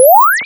Level up.wav